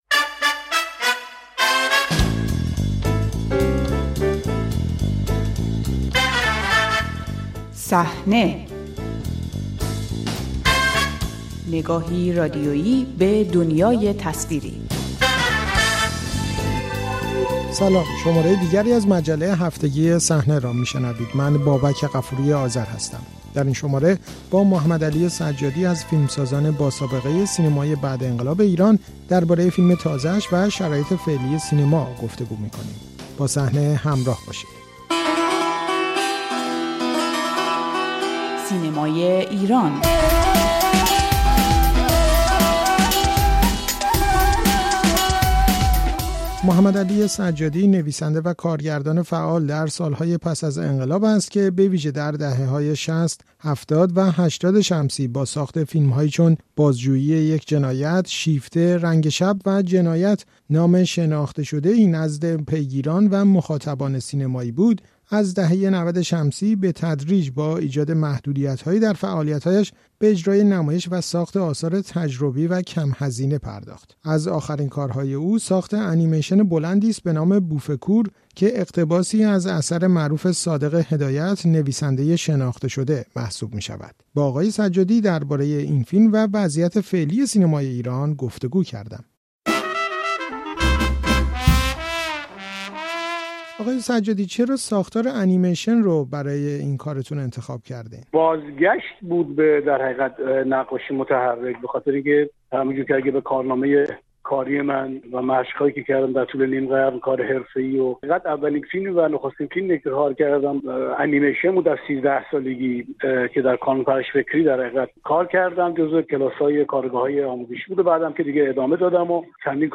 گفت‌وگو با محمدعلی سجادی درباره انیمیشن «بوف کور» و شرایط سینمای ایران